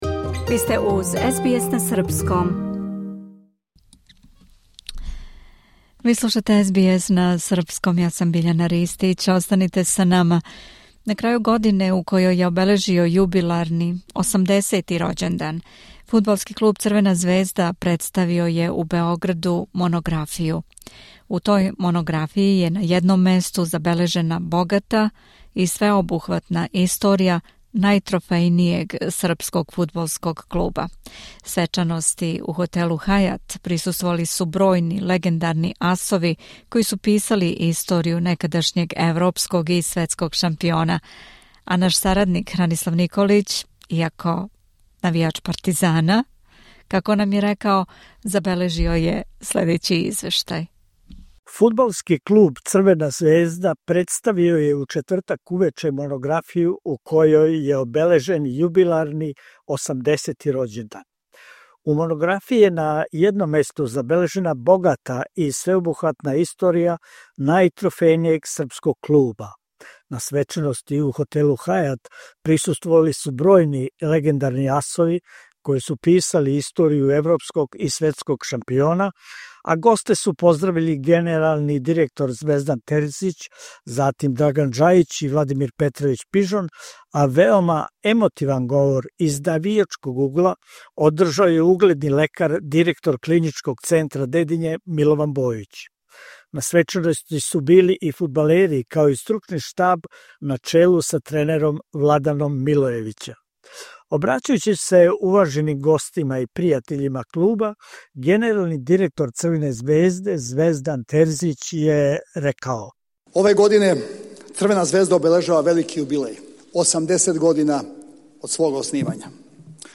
На крају године у којој је обележио јубиларни 80. рођендан, Фудбалски клуб Црвена звезда представио је у Београду монографију у којој је на једном месту забележена богата и свеобухватна историја најтрофејнијег српског фудбалског клуба. Свечаности у хотелу "Хајат" присуствовали су бројни легендарни асови који су писали историју некадашњег европског и светског шампиона.